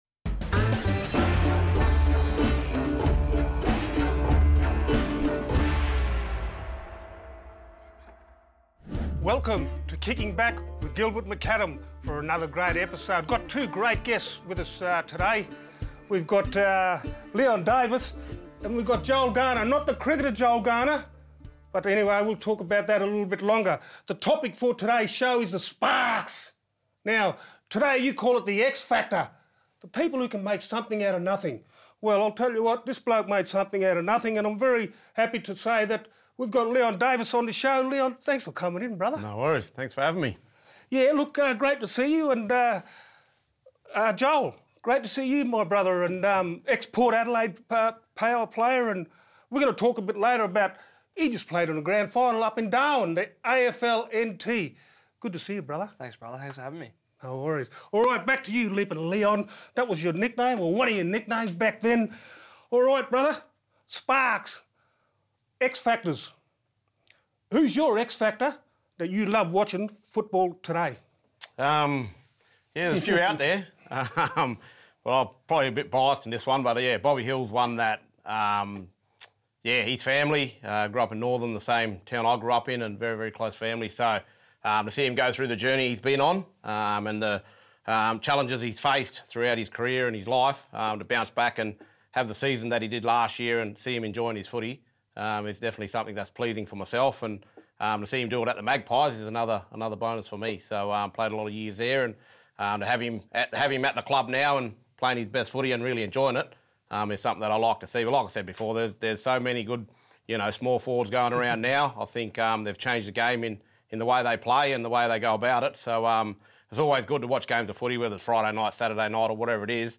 This week on NITV's latest AFL show - Kickin' Back With Gilbert McAdam, Gilly is joined by Leon Davis of the Collingwood Magpies and Joel Garner of Port Adelaide.